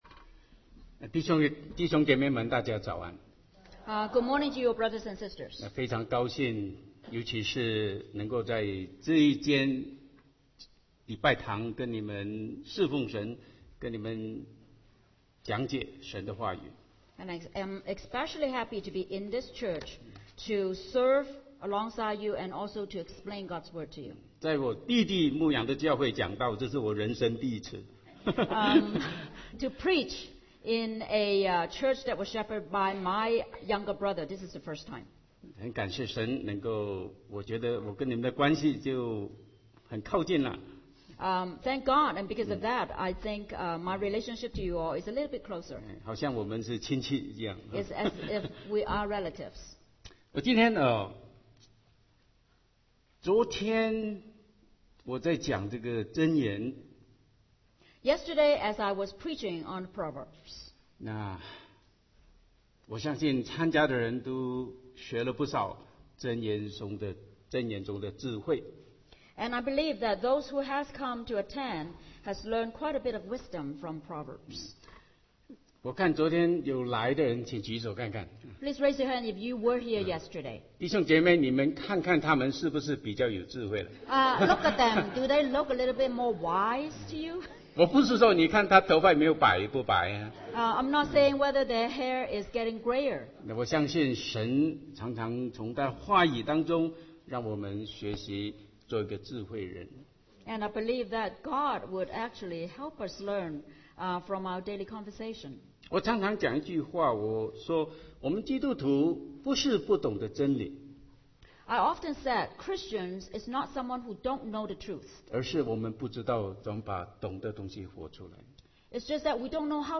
Sermon 2009-10-04 Six Foolish Things to Avoid